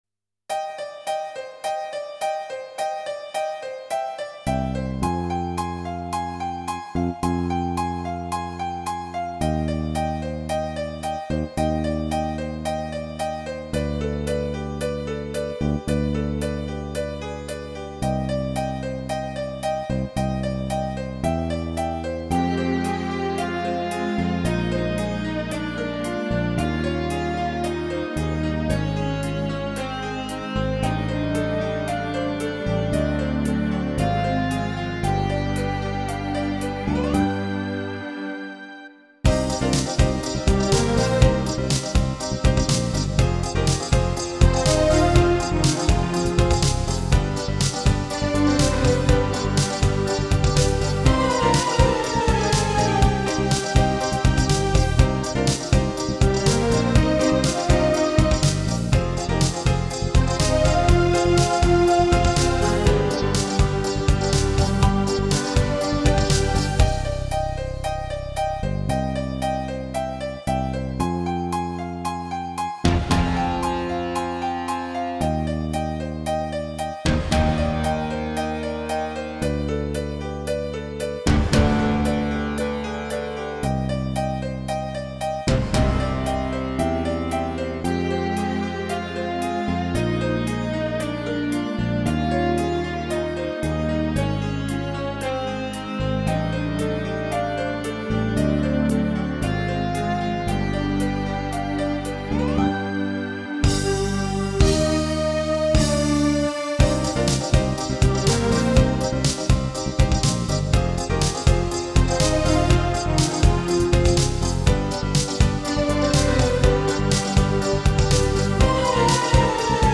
Минусовку могу предложить - сами споете на досуге!